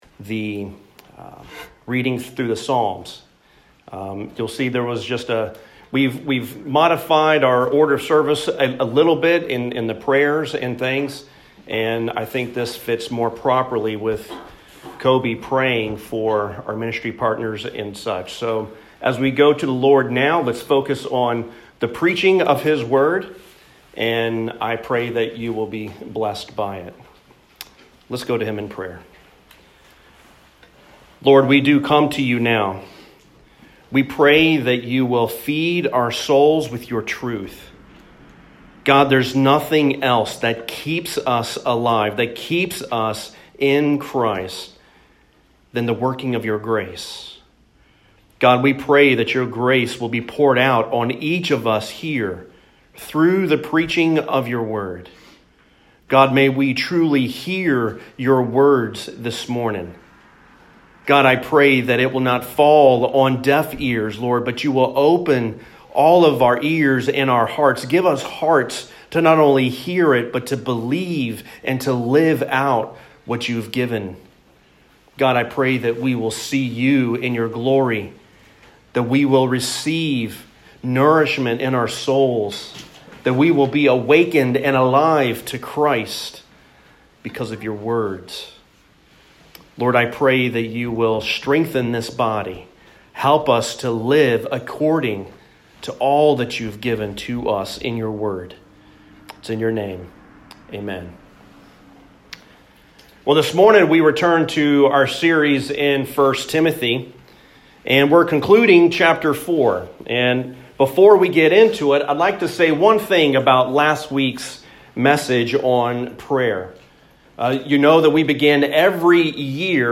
There are 3 ways the hope of gospel ministry is given to the church. Sermon Outline […]